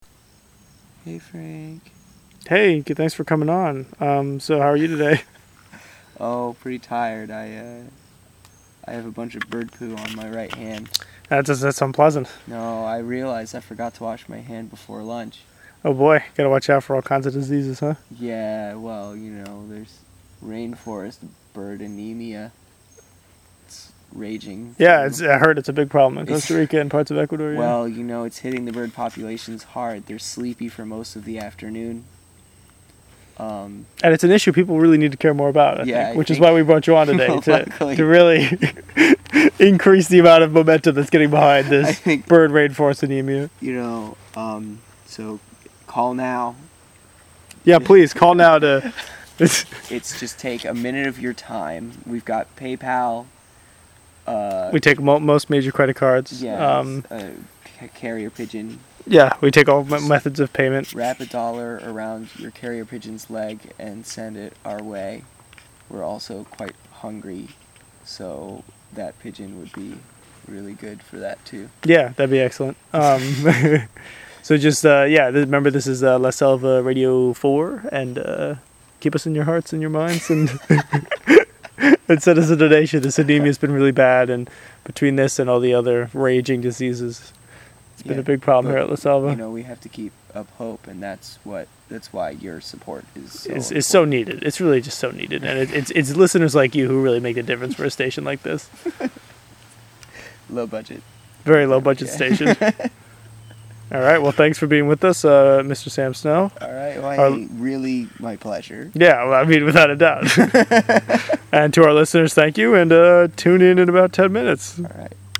We went through many iterations while working out the methods- here’s a test of the voice-recorder method on a particularly slow afternoon: